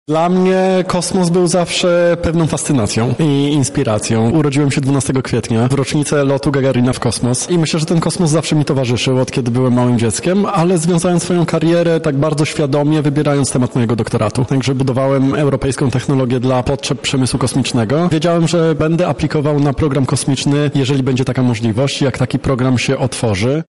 – mówił Sławosz Uznański-Wiśniewski, naukowiec Europejskiej Agencji Kosmicznej